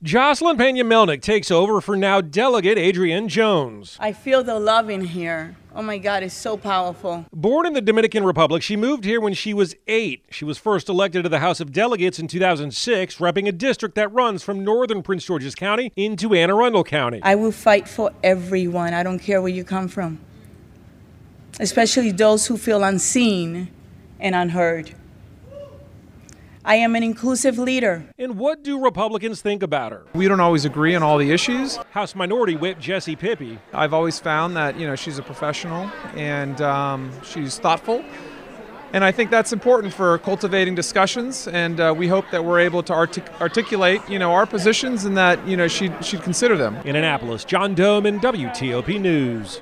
reports on Del. Joseline Pena-Melnyk becoming the new speaker of the Maryland House of Delegates